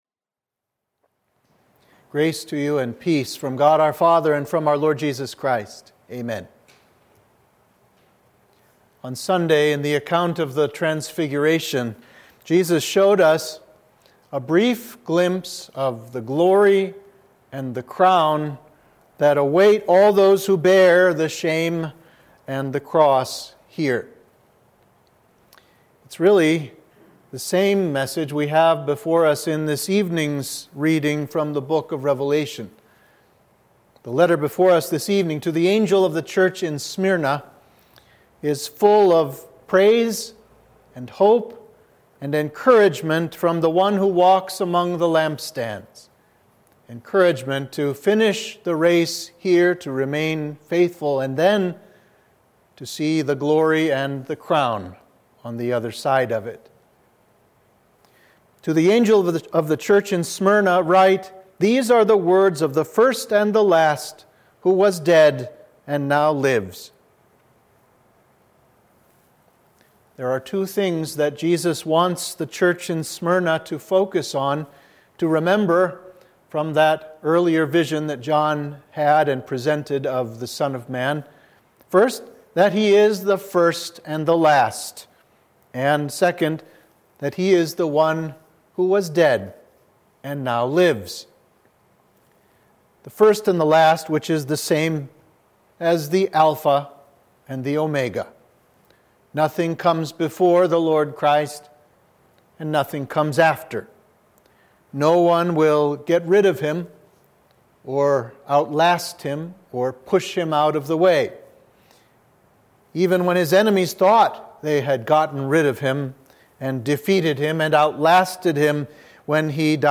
Sermon for midweek of Transfiguration